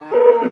cow_hurt3.ogg